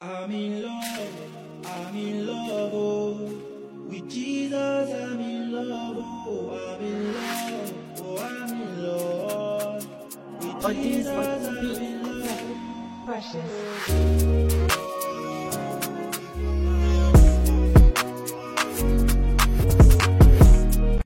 addictive hook, and sun-soaked energy